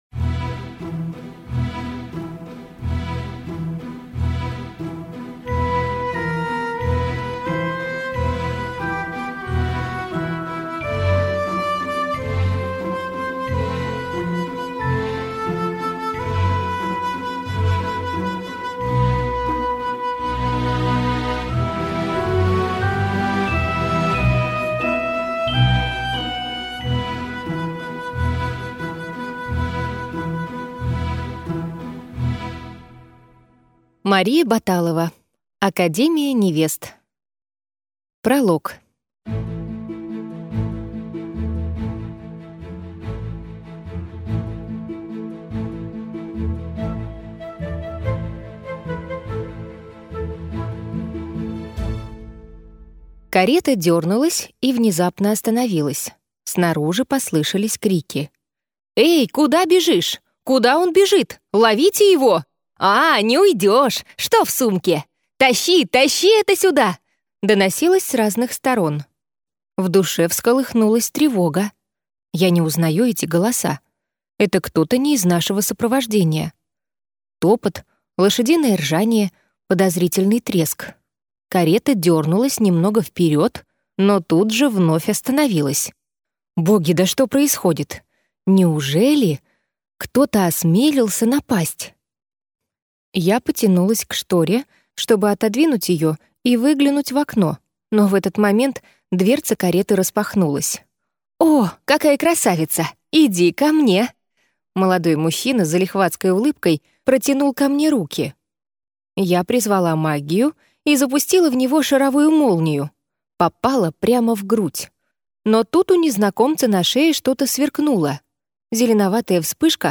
Аудиокнига Академия невест - купить, скачать и слушать онлайн | КнигоПоиск